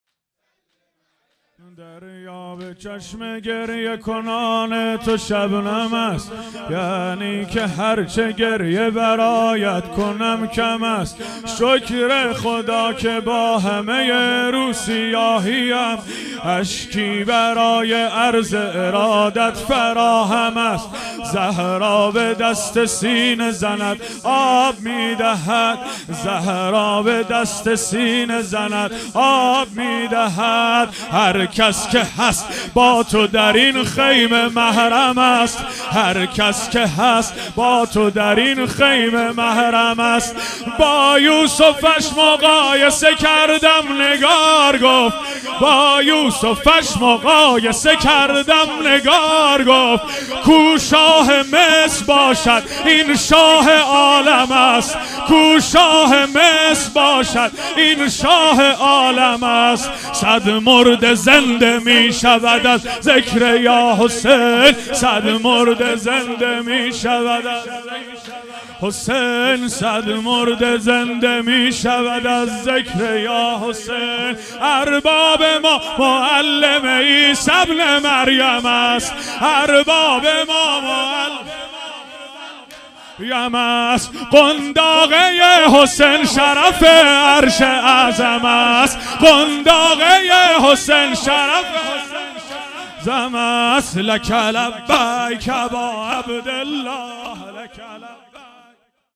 هیئت حسن جان(ع) اهواز - واحد | دریا به چشم گریه کنان تو